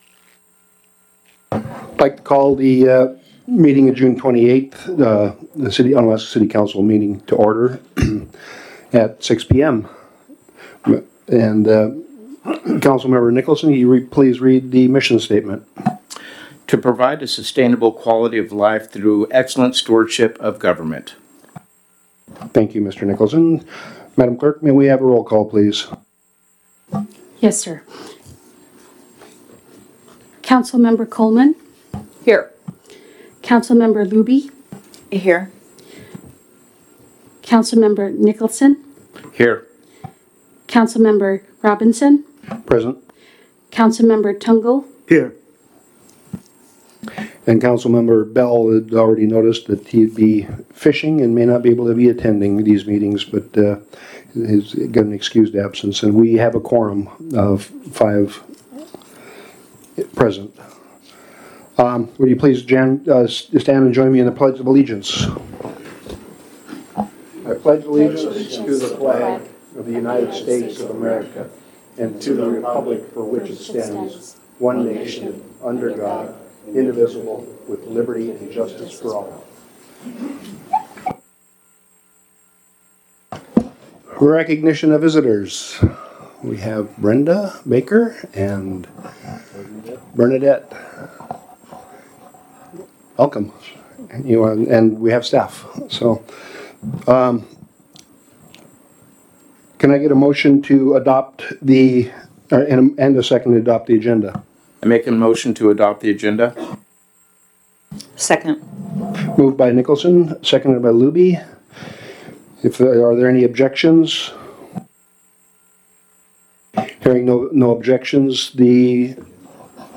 Council Meeting - June 28, 2022 | City of Unalaska - International Port of Dutch Harbor
In person at City Hall (43 Raven Way)